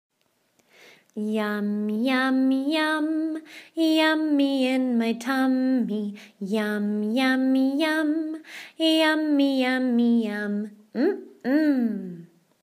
Mmm Sound - Banana